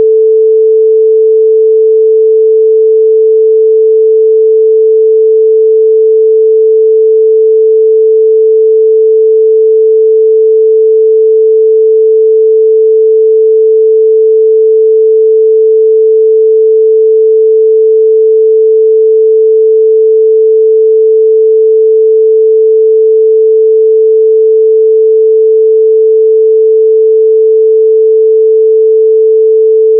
440hz.wav